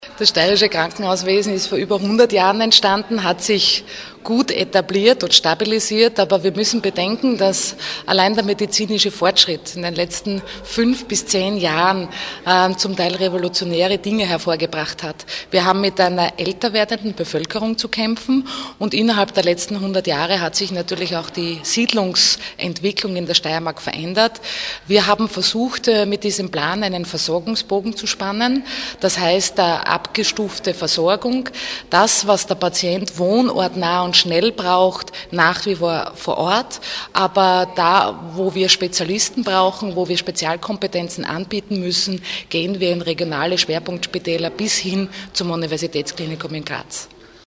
O-Ton: Strukturreform der steirischen Krankenanstalten präsentiert
Gesundheitslandesrätin Kristina Edlinger-Ploder: